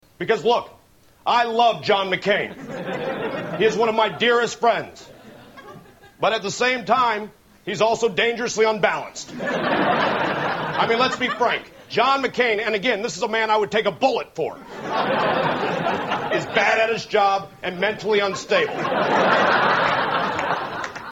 Tags: SNL clip Sarah Palin Sarah Palin Tina Fey Tina Fey Tina Fey impression